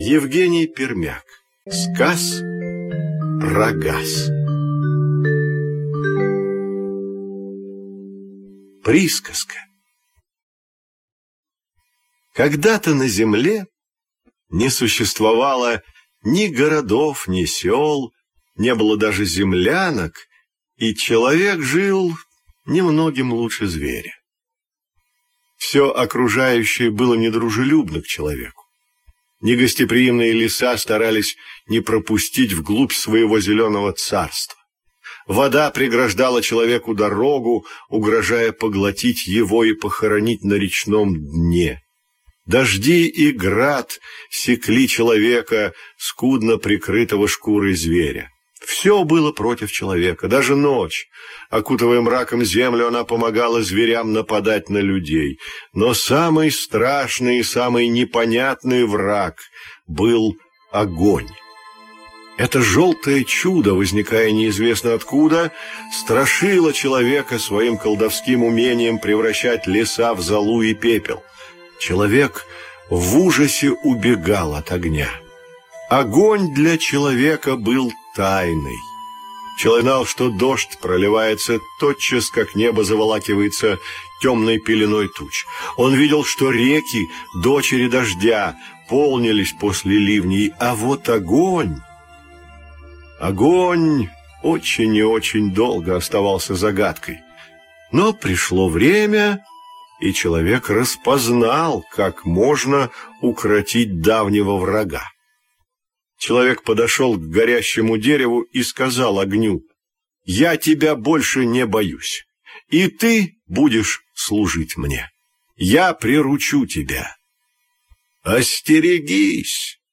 Сказ про газ - аудиосказка Пермяка - слушать онлайн